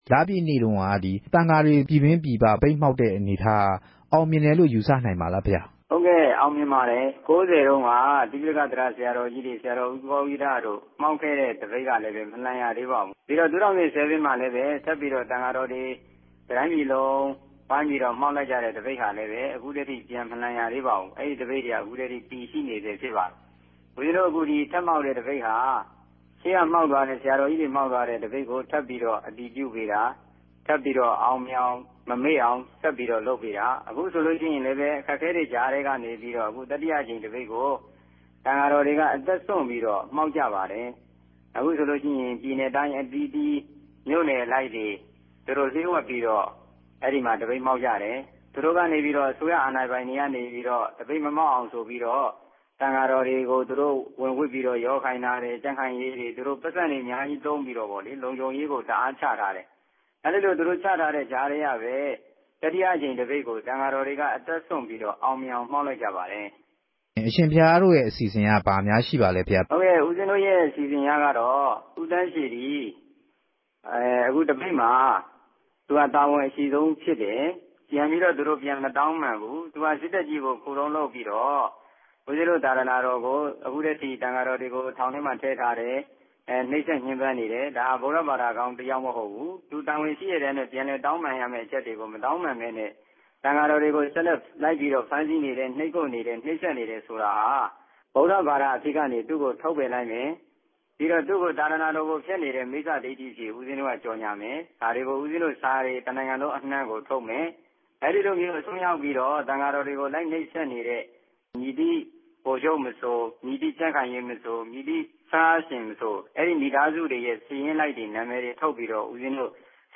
သံဃာ့ တပ်ပေၝင်းစုအဖြဲႚဝင် ဆရာတော်တပၝးက ခုလိုမိန်ႛုကားပၝတယ်။
ဆက်သြယ်မေးူမန်းခဵက်။